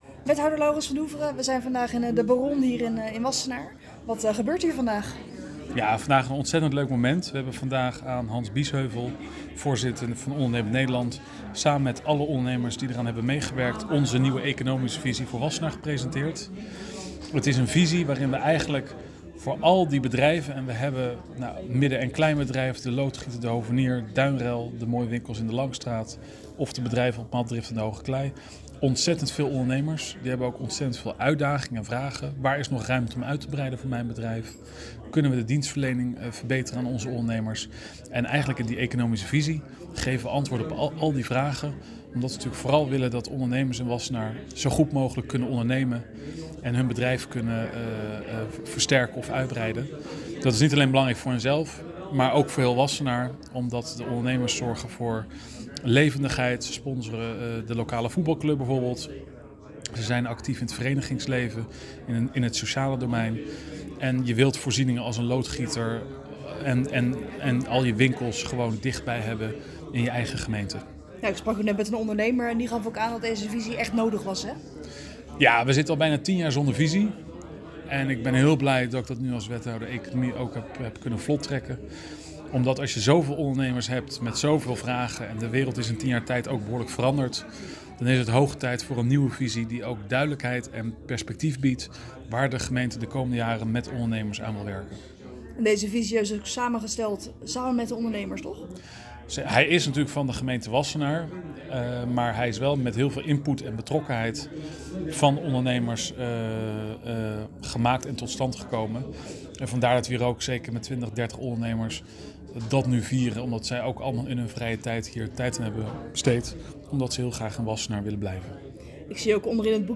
in gesprek met wethouder Laurens van Doeveren over de Economische Visie.